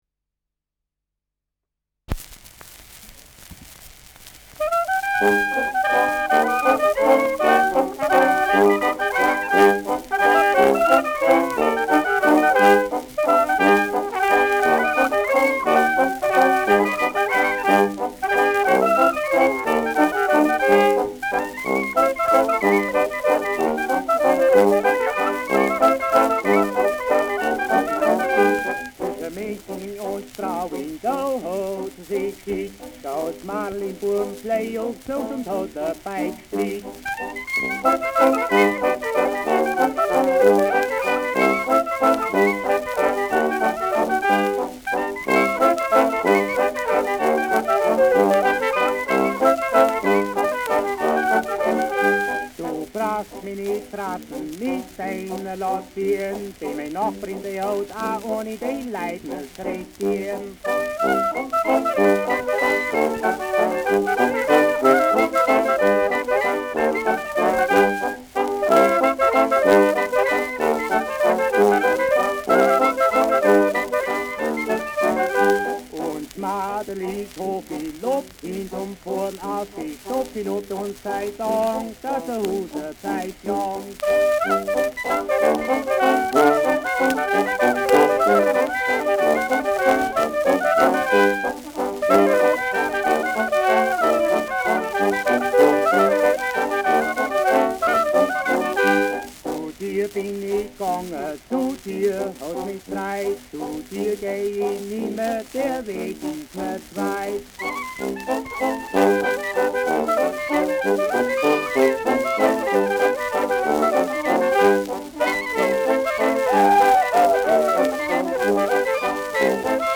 Zwischen München und Straubing : Ländler mit Gesang
Schellackplatte
Tonrille: Abrieb : Kratzer 2 Uhr
Kapelle Die Alten, Alfeld (Interpretation)